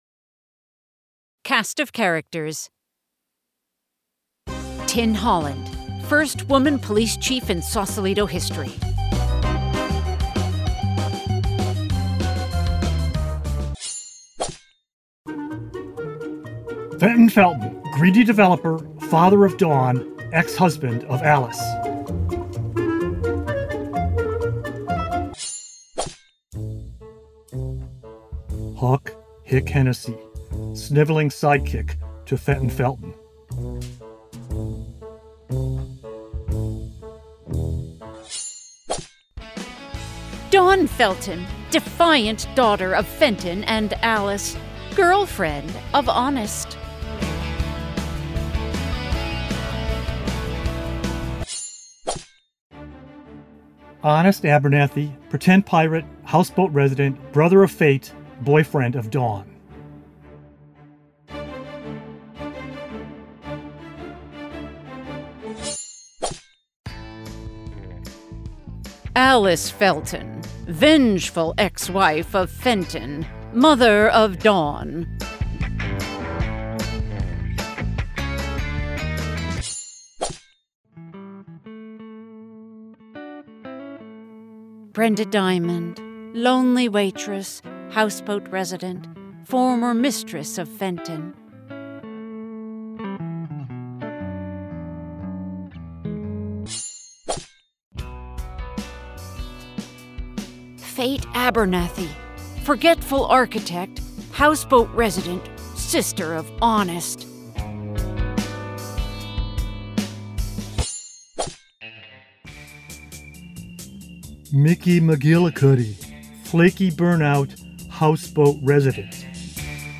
You can hear the musical stings and description for each character below, in the cast list that comes at the beginning of the book.